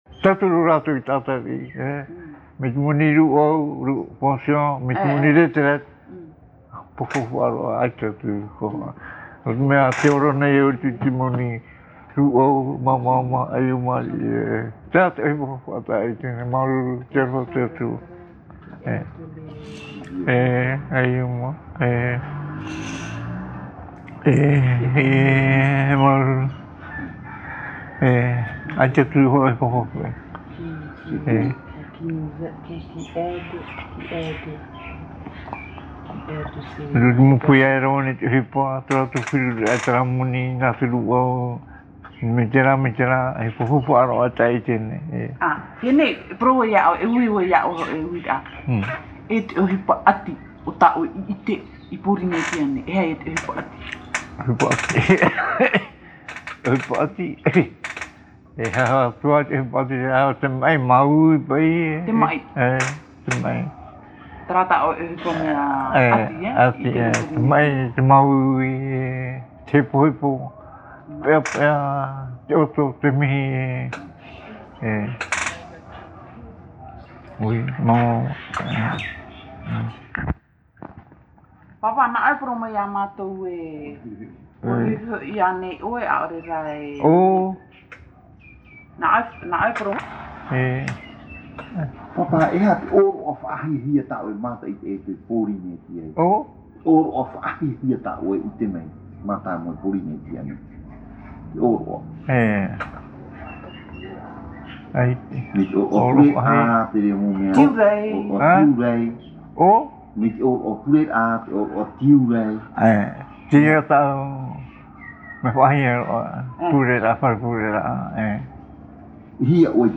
Interview réalisée à Tīpaerui sur l’île de Tahiti.
Papa mātāmua / Support original : cassette audio